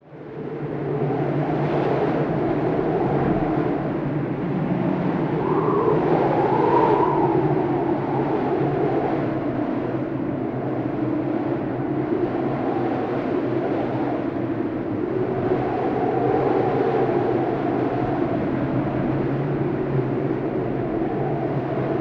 Звуки шторма
Гул сильного ветра в горах